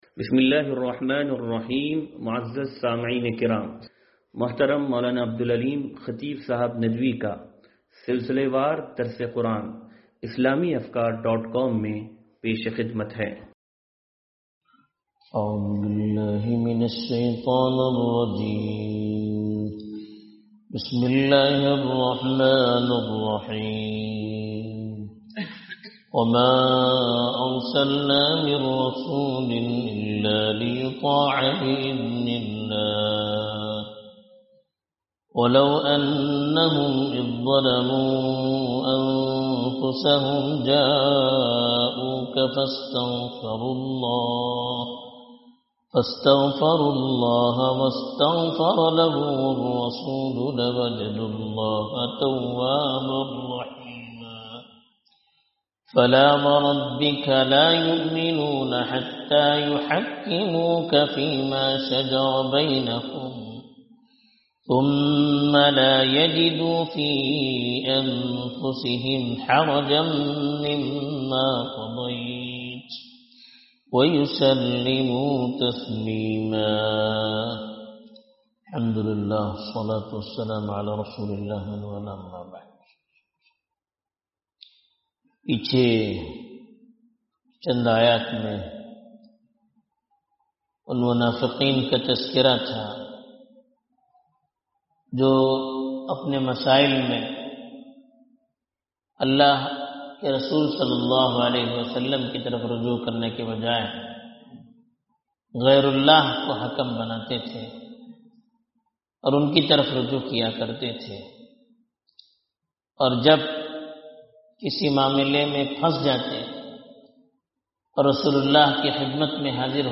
درس قرآن نمبر 0363